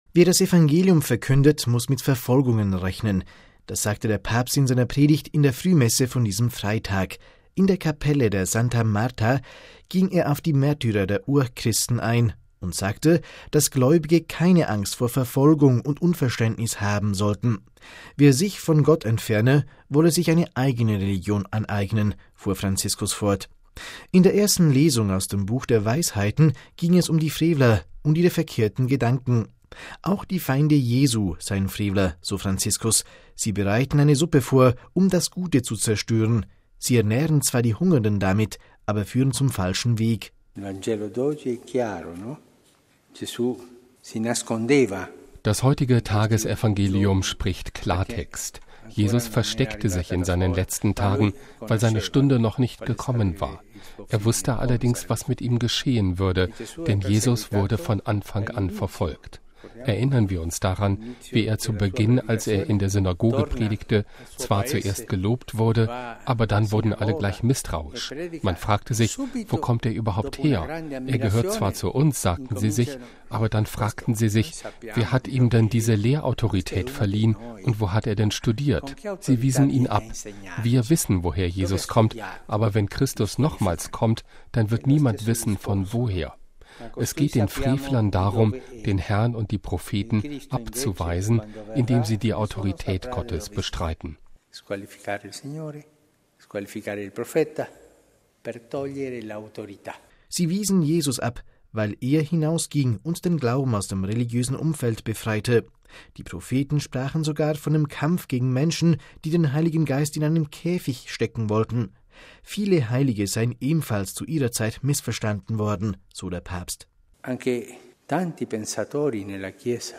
Das sagte der Papst in seiner Predigt in der Frühmesse von diesem Freitag. In der Kapelle der Casa Santa Marta ging er auf die Märtyrer der Urchristen ein und betonte, dass Gläubige keine Angst vor Verfolgung oder Unverständnis haben sollten.